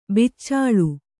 ♪ bicc`ḷu